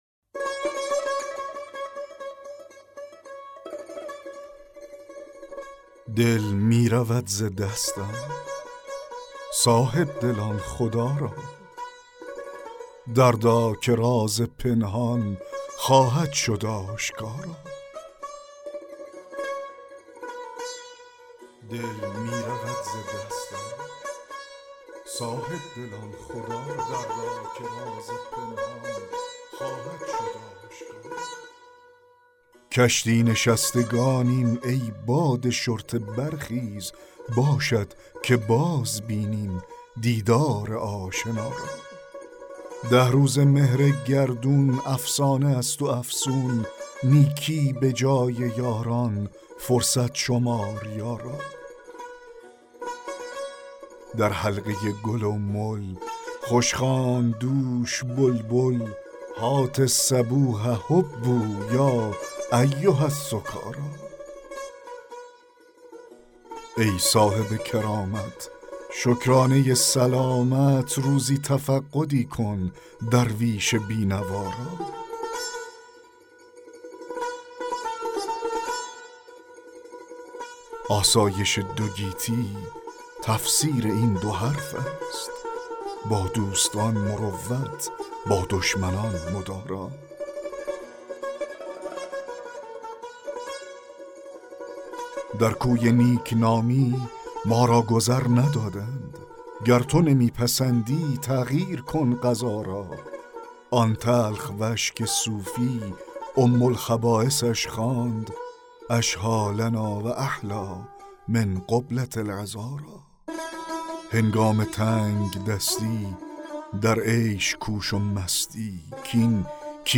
دکلمه غزل 5 حافظ